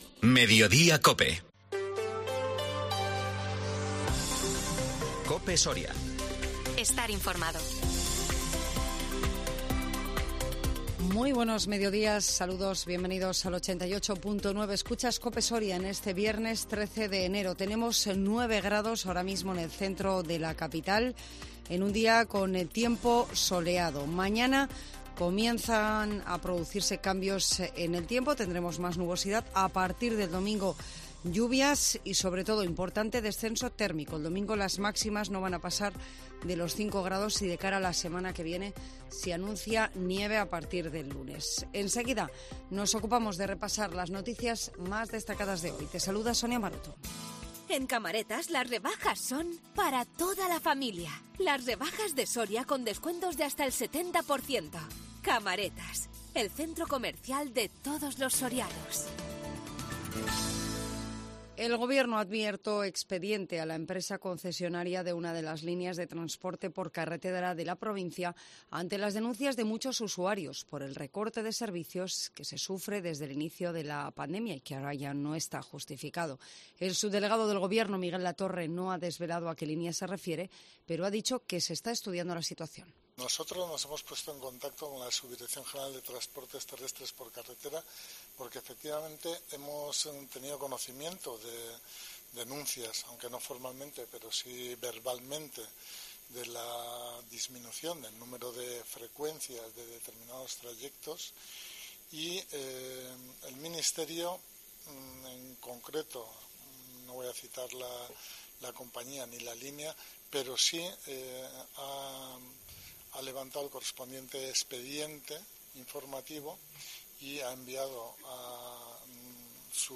INFORMATIVO MEDIODÍA COPE SORIA 13 ENERO 2023